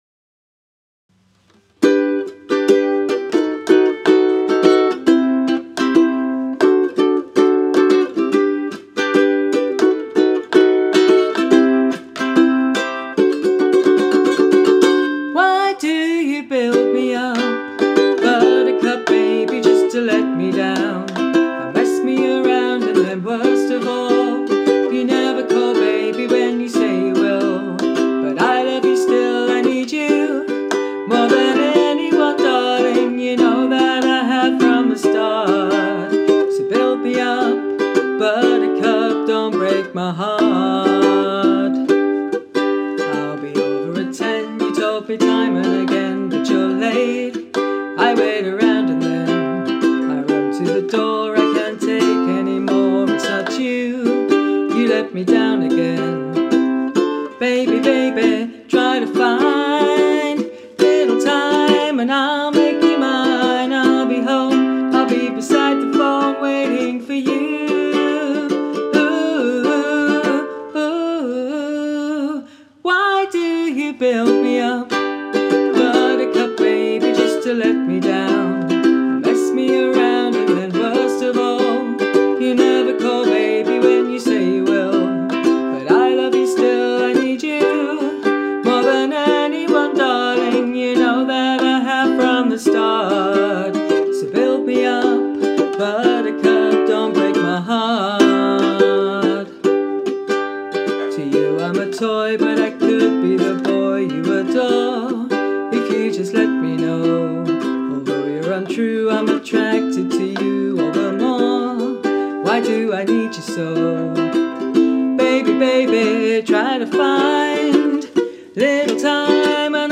Had a Sunday working through some new songs on ukulele.